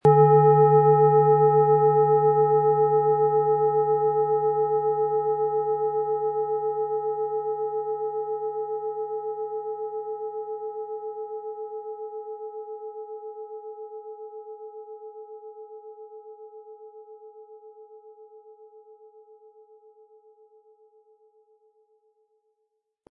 Planetenton
Im Sound-Player - Jetzt reinhören können Sie den Original-Ton genau dieser Schale anhören.
Im Preis enthalten ist ein passender Klöppel, der die Töne der Schale schön zum Schwingen bringt.
SchalenformBihar
MaterialBronze